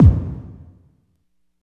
30.06 KICK.wav